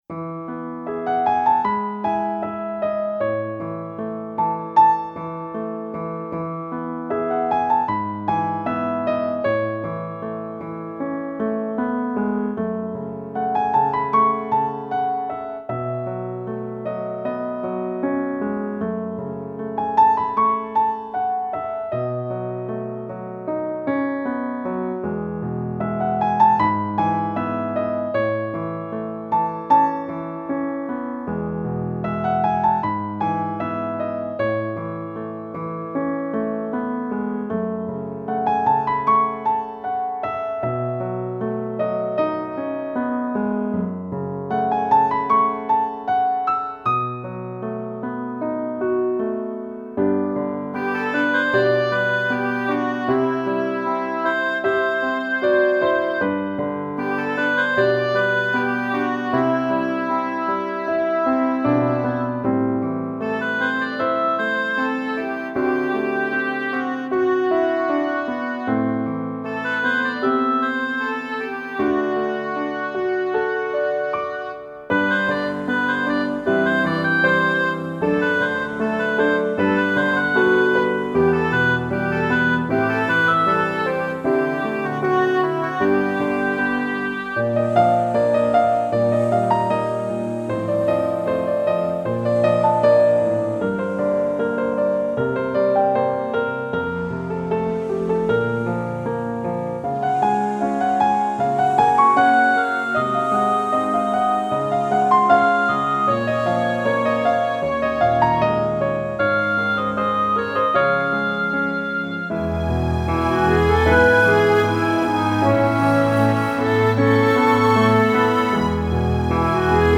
白金录音室缔造完美钢琴至尊试音天碟
清新纯净的钢琴演奏，舒缓平静，仿佛安逸甜美的呼吸一般。弦乐的烘托，演绎日出与月落的对话。